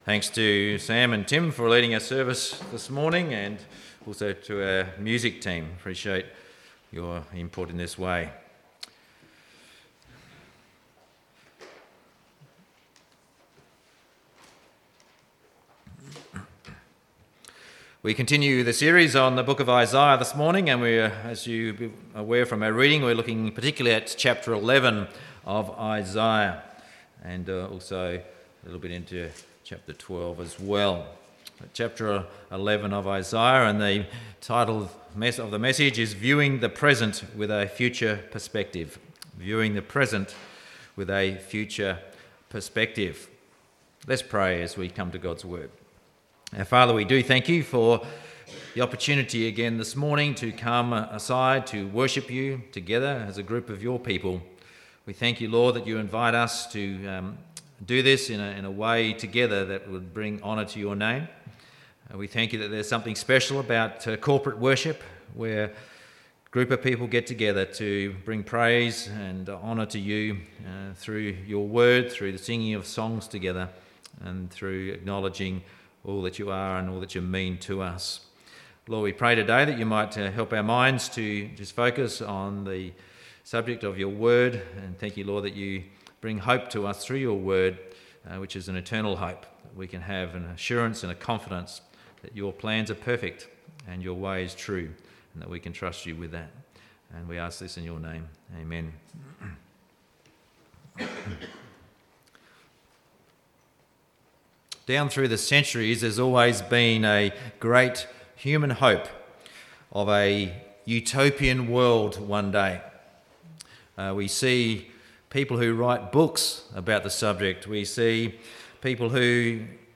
Sunday Service Audio 13/8/17